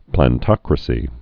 (plăn-tŏkrə-sē)